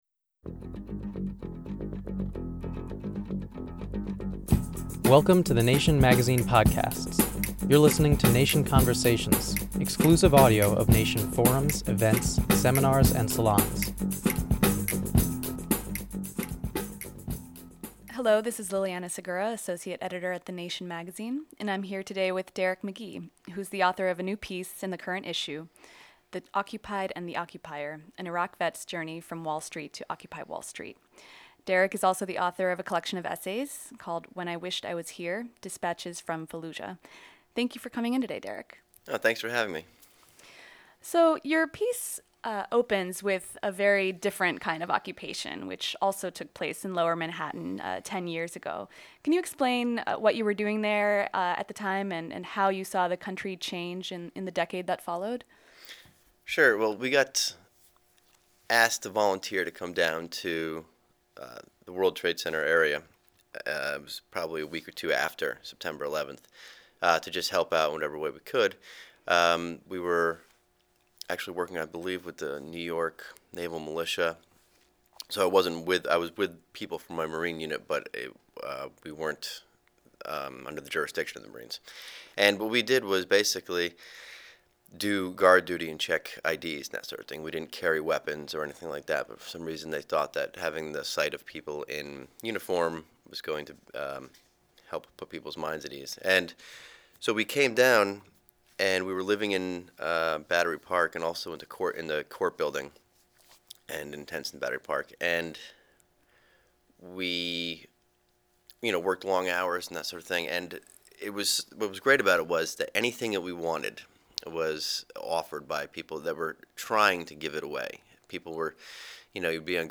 Subscribe to Nation Conversations on iTunes for exclusive audio of Nation editors and writers digging into the topics and issues that shape the magazine.